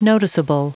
Prononciation du mot noticeable en anglais (fichier audio)
Prononciation du mot : noticeable
noticeable.wav